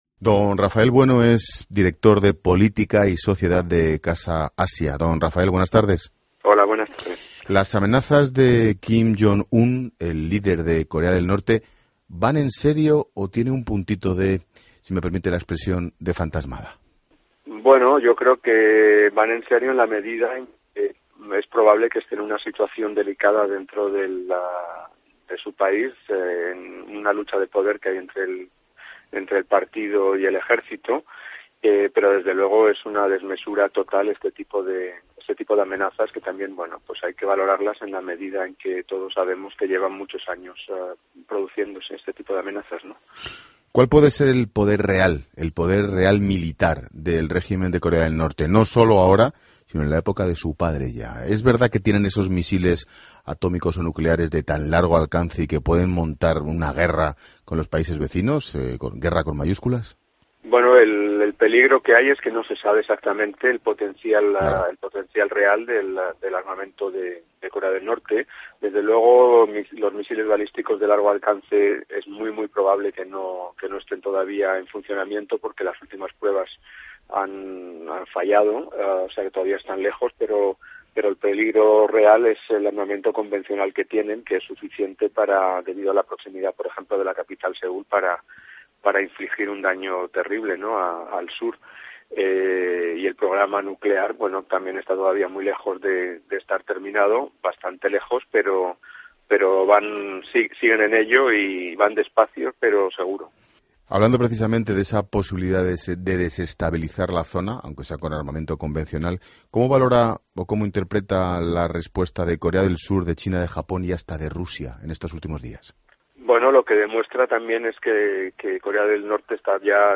Reportajes COPE